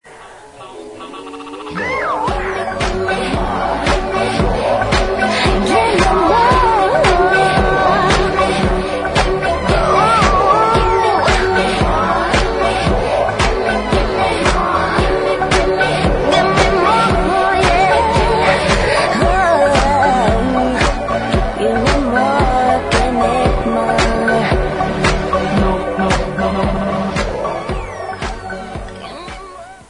• Rock Ringtones